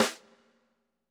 26 snare 1 hit.wav